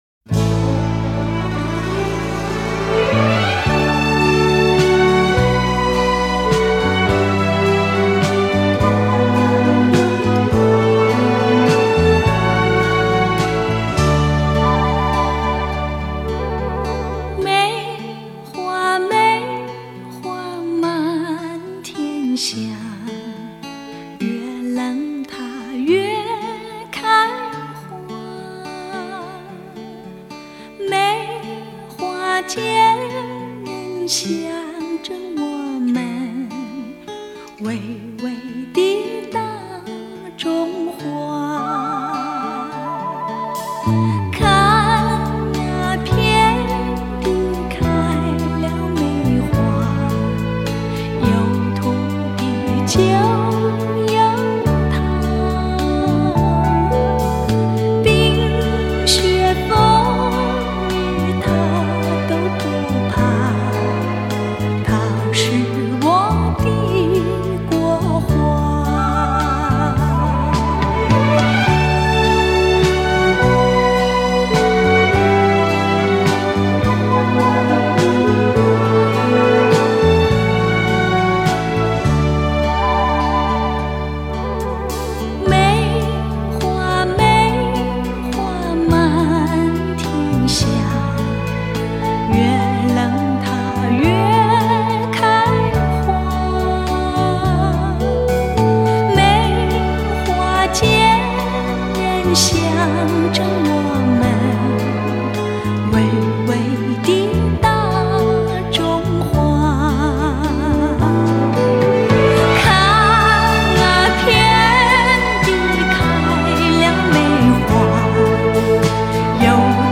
DXD重新编制